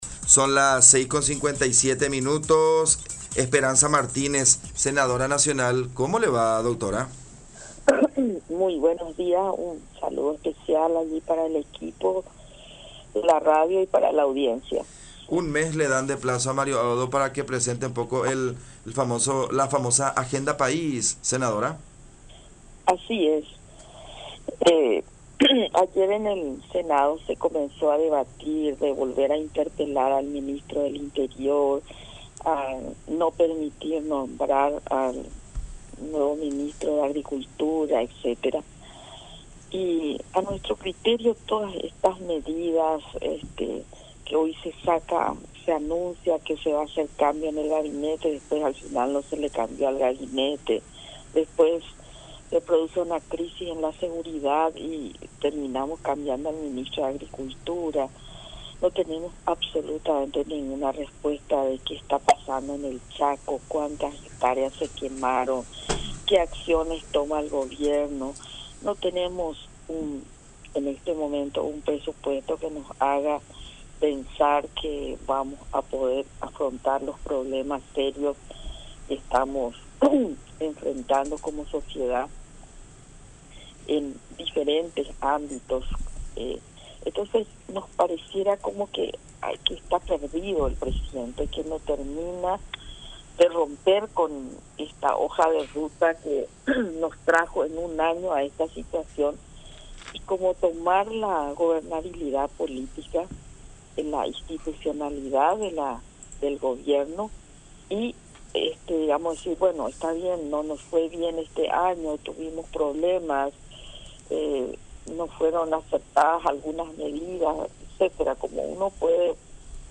“Había anunciado cambios en el Gabinete y después no le cambió a nadie; hay crisis en la seguridad y terminamos cambiando al ministro de Agricultura; no tenemos absolutamente respuestas sobre lo que está pasando en el Chaco y qué acciones toma el Gobierno… El presidente pareciera que está perdido en su hoja de ruta que nos llevó a esta situación”, expresó Martínez en diálogo con La Unión.
07-Esperanza-Martínez-Senadora-Nacional.mp3